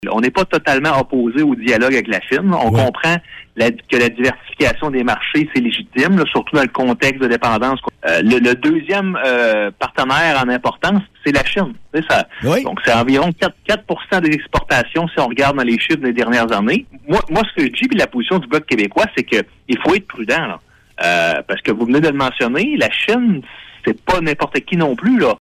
Monsieur Blanchette-Joncas a indiqué sur les ondes de Réveil Rimouski que le gouvernement se rapproche d’un pays pourtant identifié comme dangereux en ce qui a trait à la sécurité nationale, souvent taxé d’ingérence et d’espionnage.